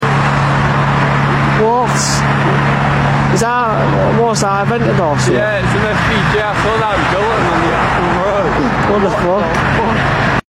Random Lamborghini SVJ Being Transported